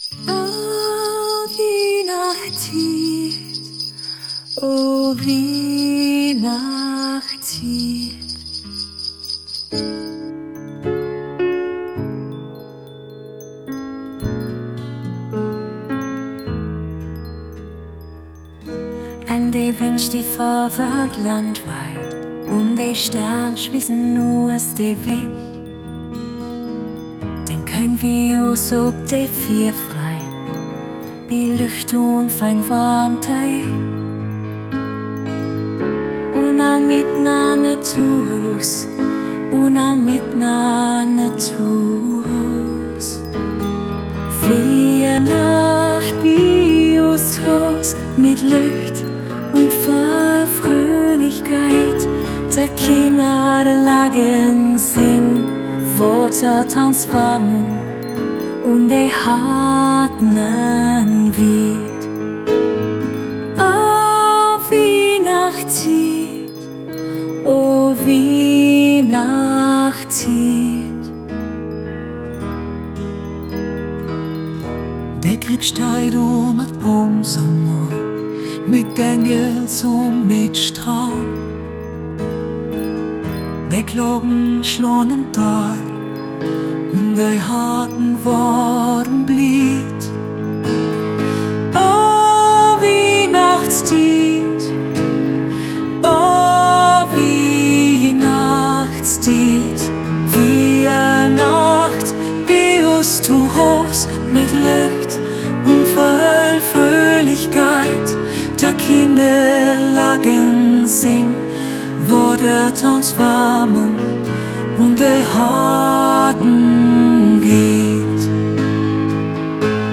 Plattdeutsche Musik und mehr.